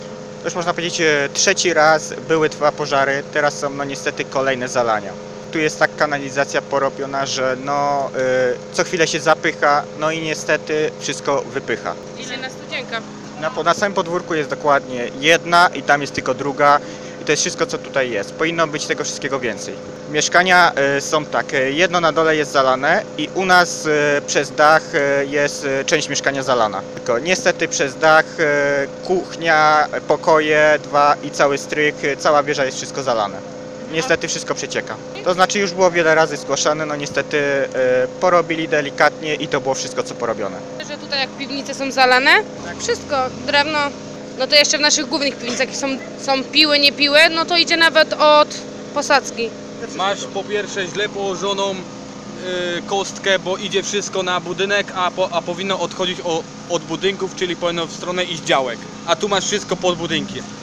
Najgorzej było na ulicy Gnieźnieńskiej w Żninie, gdzie obfite opady deszczu spowodowały zalania mieszkań i piwnic. Jak mówią mieszkańcy sytuacja przy okazji ulewnego deszczu powtarza się po raz kolejny.
1_mieszkancy_gnieznienska.mp3